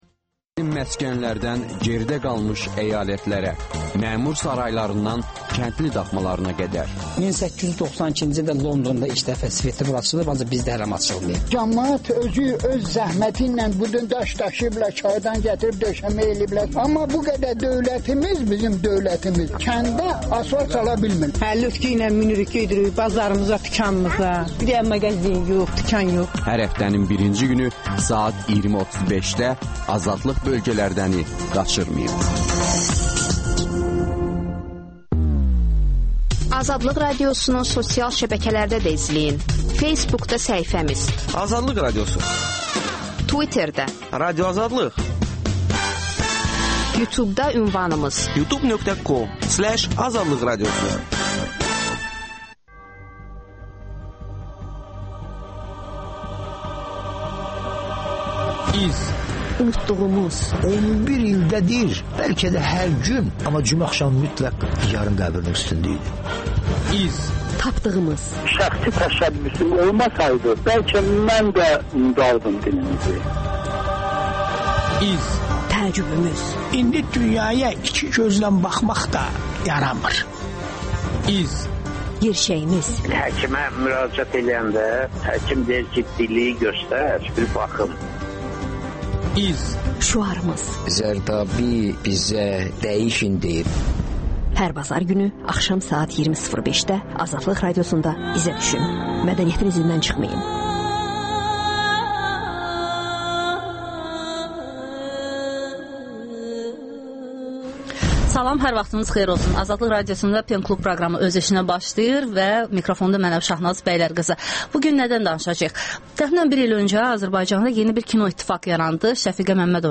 radiodebatı.